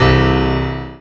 55y-pno09-g3.wav